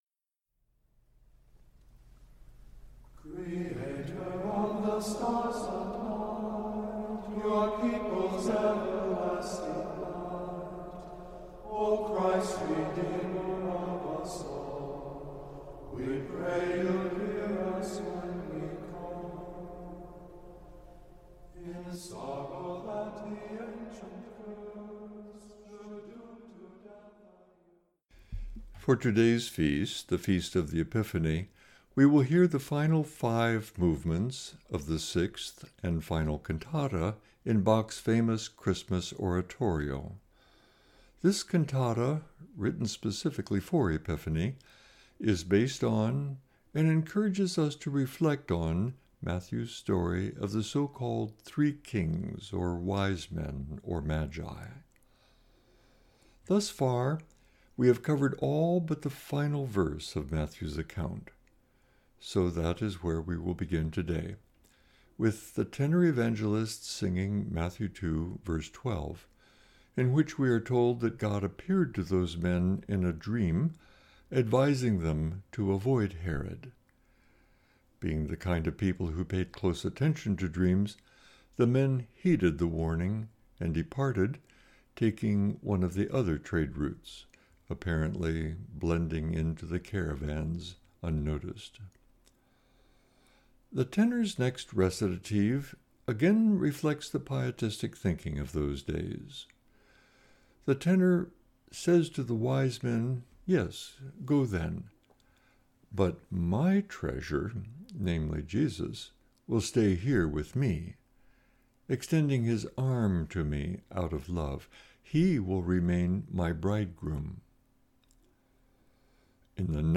Meditation - Point Grey Inter-Mennonite Fellowship